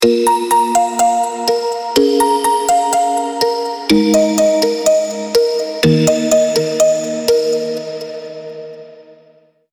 115-130 bpm